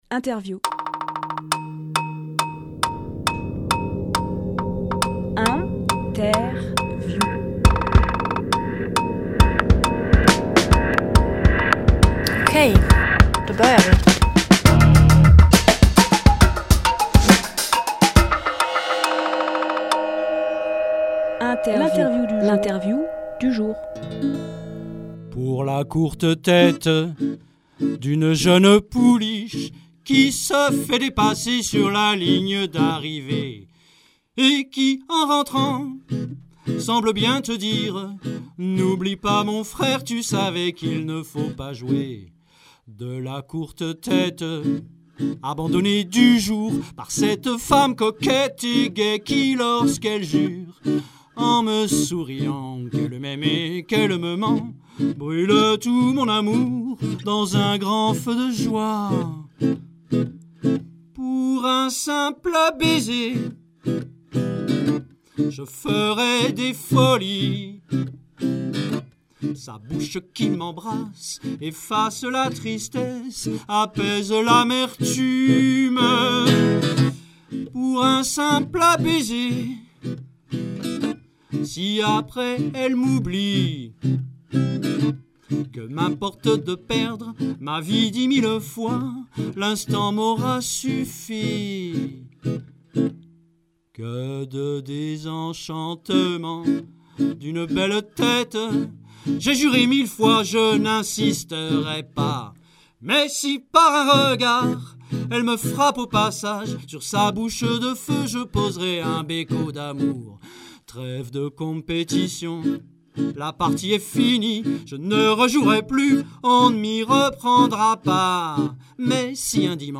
Interview
Lieu : Studio RDWA